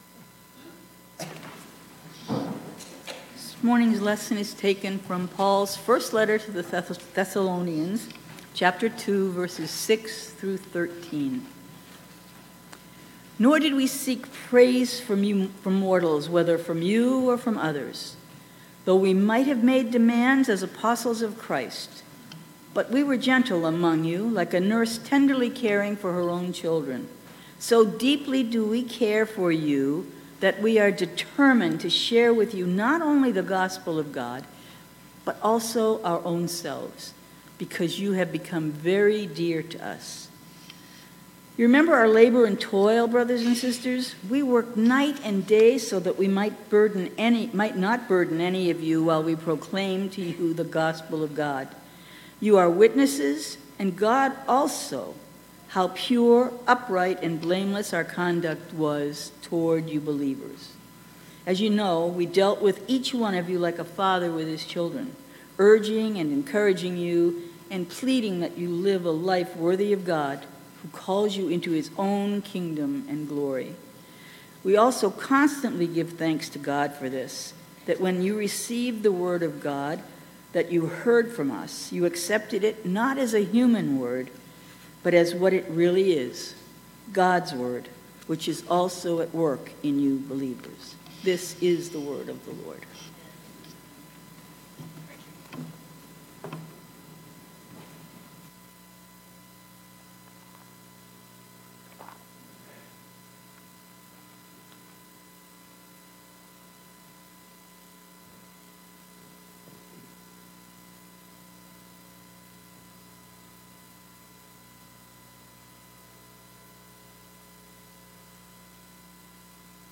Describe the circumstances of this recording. Sunday, September 4, 2022 – First Congregational Church Hanson